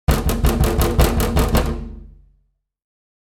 Scary-window-knocking-sound-effect.mp3